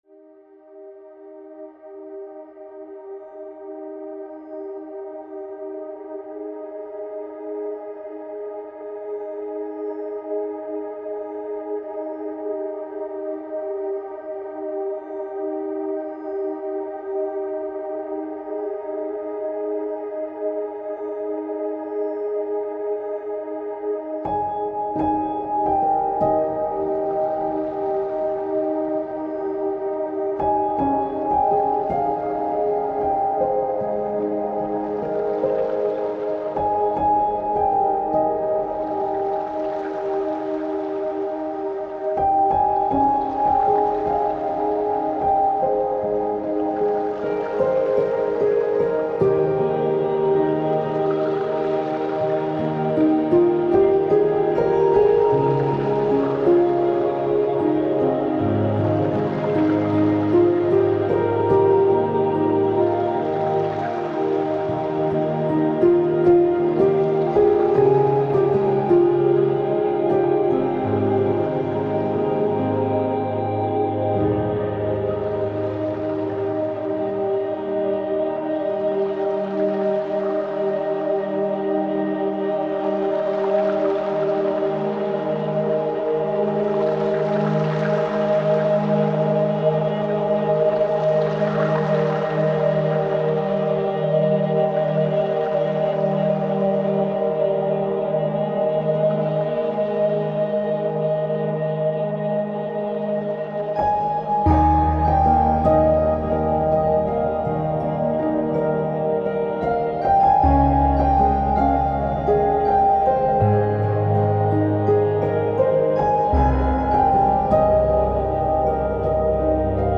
موسیقی کنار تو
موسیقی بی کلام مدیتیشن
سبک آرامش بخش , پیانو , عصر جدید , مدیتیشن , موسیقی بی کلام